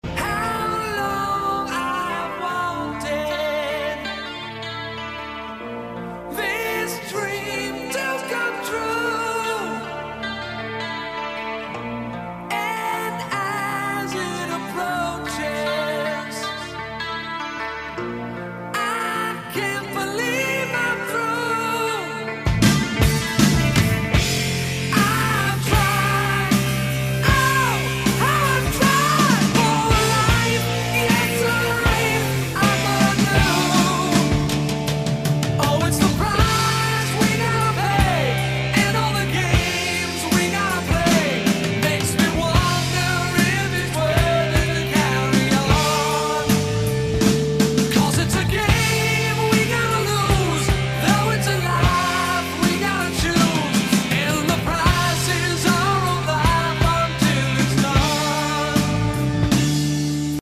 рок рингтоны